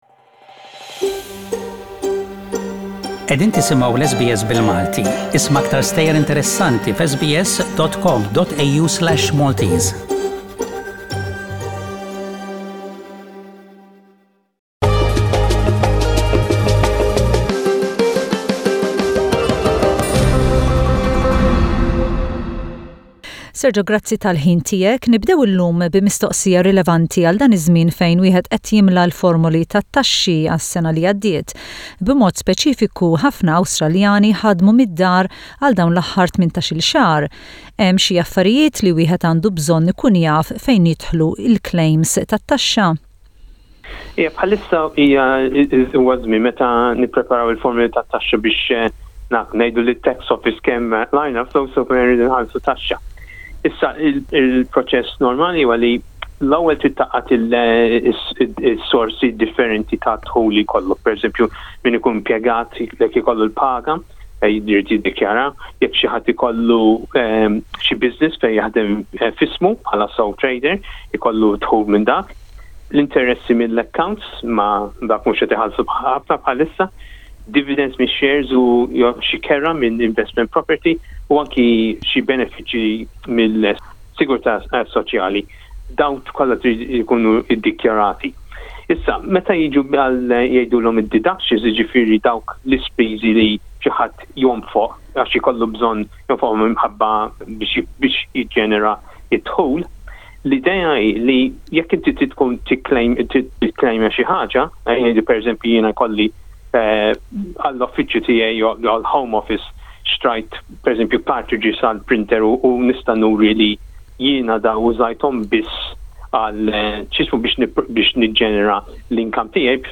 Financial consultant